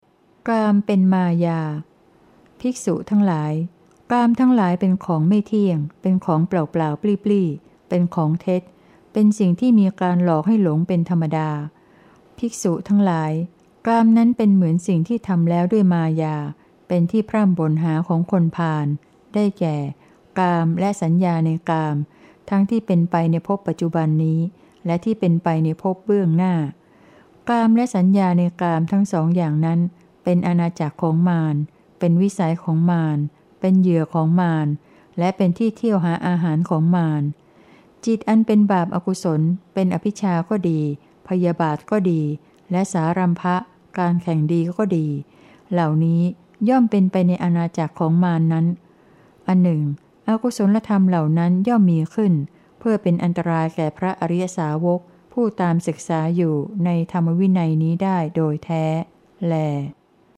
เสียงอ่าน